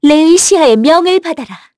Lewsia_A-Vox_Skill7-2_kr.wav